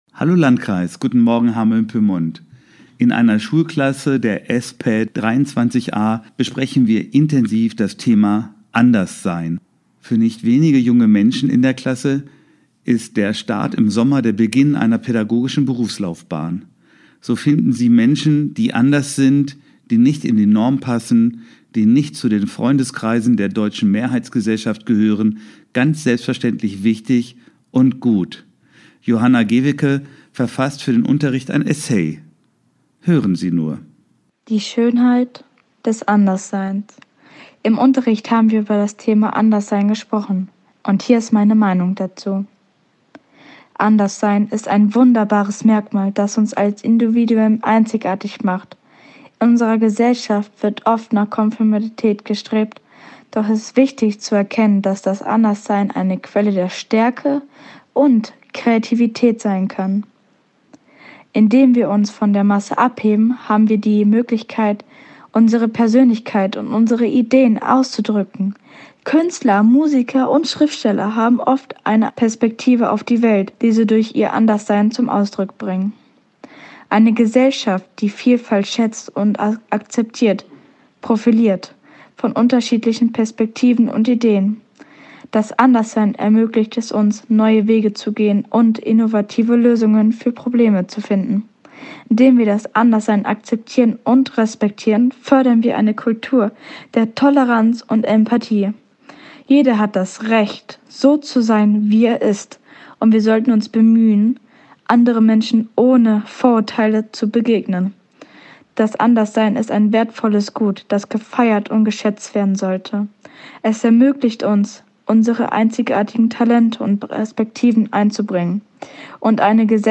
Radioandacht vom 22. September